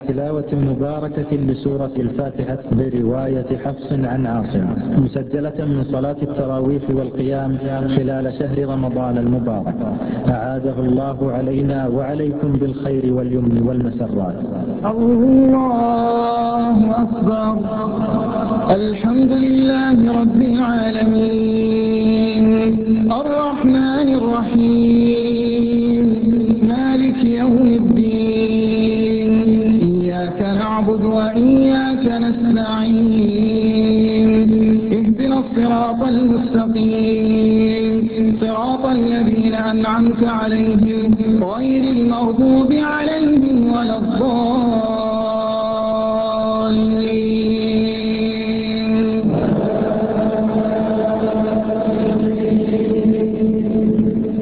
Recitation Of the Quran